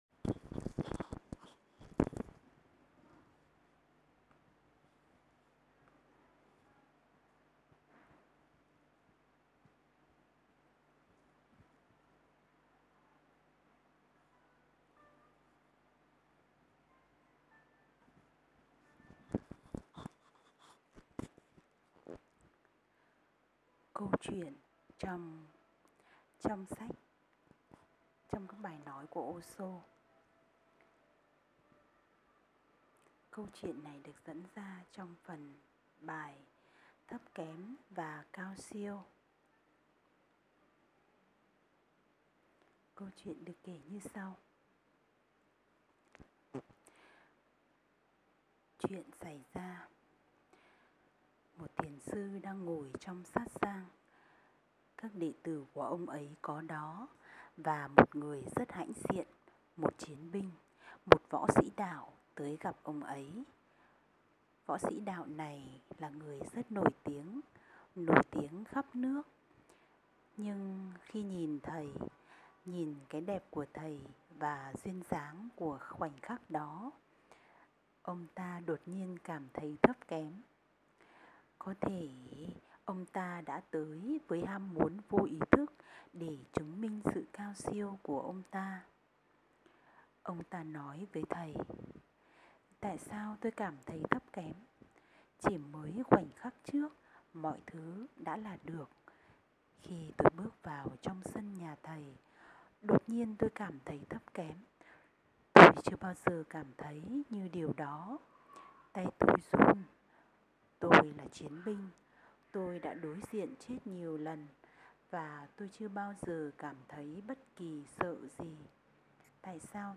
Sách nói